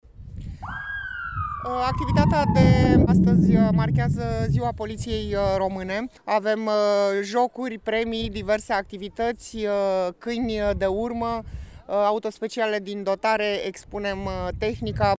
Inspectoratul Județean de Poliție Constanța a organizat astăzi, în piața Ovidiu din Constanța, un eveniment care a marcat, în avans, Ziua Poliției Române, sărbătorite pe 25 martie.